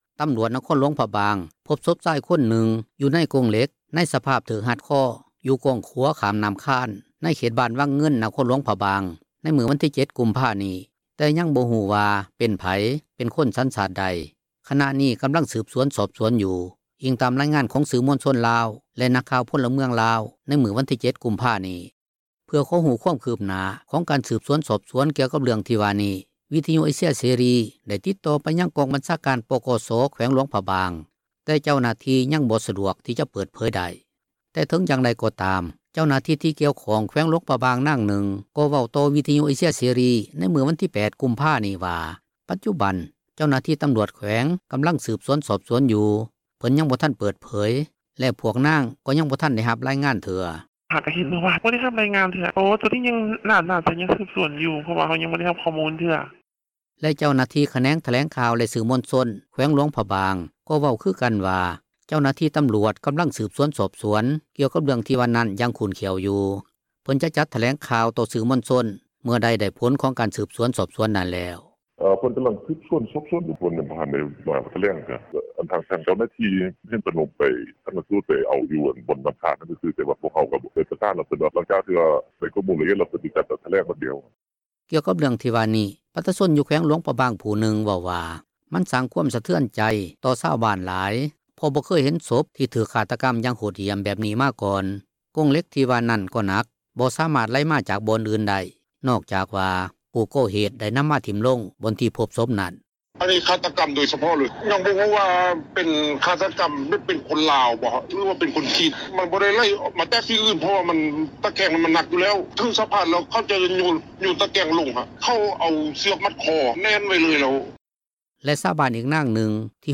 ເຖິງຢ່າງໃດກໍຕາມ ເຈົ້າໜ້າທີ່ ທີ່ກ່ຽວຂ້ອງ ແຂວງຫລວງພຣະບາງນາງນຶ່ງ ເວົ້າຕໍ່ວິທຍຸເອເຊັຽເສຣີ ໃນມື້ວັນທີ 8 ກຸມພານີ້ວ່າ ປັດຈຸບັນ ເຈົ້າໜ້າທີ່ຕໍາຣວດແຂວງ ກໍາລັງສືບສວນ, ສອບສວນ ຢູ່.
ກ່ຽວກັບເຣື່ອງທີ່ວ່ານີ້ ປະຊາຊົນຢູ່ແຂວງຫລວງພຣະບາງຜູ້ນຶ່ງ ເວົ້າວ່າ ມັນສ້າງຄວາມສະເທືອນໃຈ ຕໍ່ຊາວບ້ານຫລາຍ ເພາະບໍ່ເຄີຍເຫັນສົບ ທີ່ຖືກຄາດຕະກັມ ຢ່າງໂຫດຫ້ຽມແບບນີ້ມາກ່ອນ, ກົງເຫລັກທີ່ວ່ານັ້ນກໍໜັກ, ບໍ່ສາມາດໄຫລມາຈາກບ່ອນອື່ນໄດ້ ນອກຈາກວ່າ ຜູ້ກໍ່ເຫດໄດ້ນໍາມາຖິ້ມລົງ ບ່ອນທີ່ພົບສົບນັ້ນ.